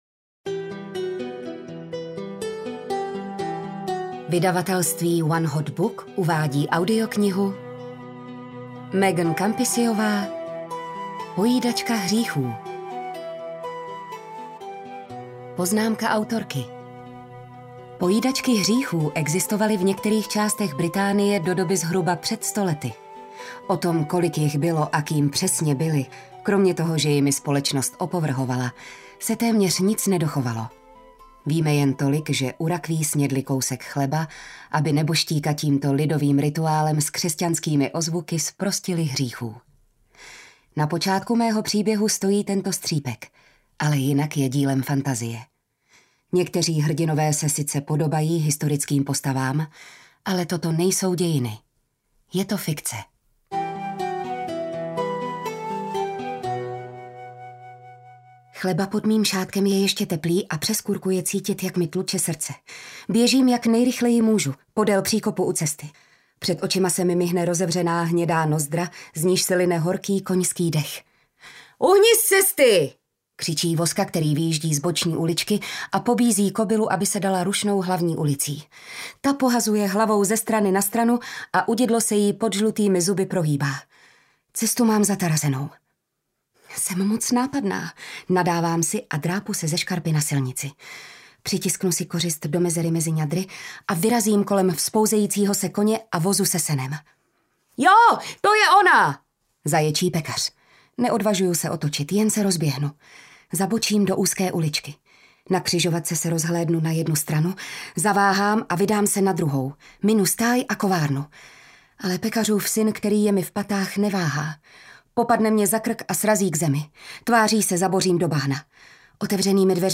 Pojídačka hříchů audiokniha
Ukázka z knihy